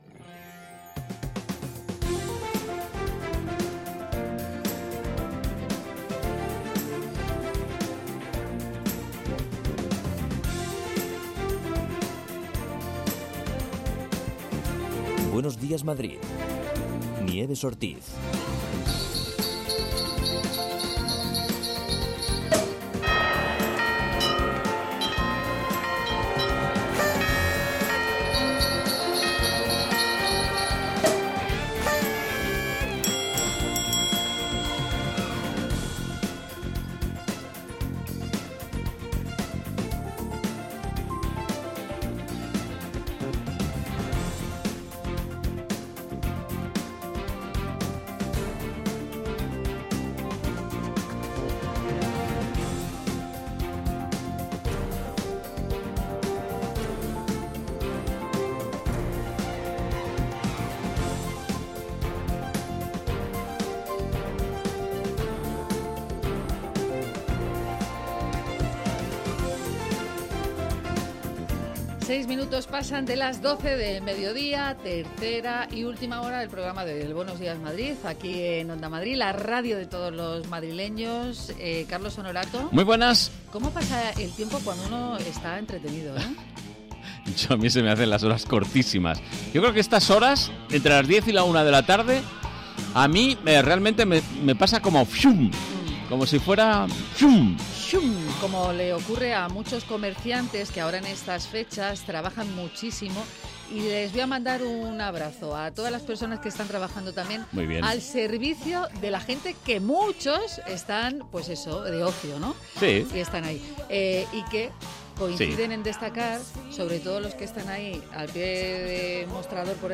Tres horas más de radio donde se habla de psicología, ciencia, cultura, gastronomía, medio ambiente y consumo.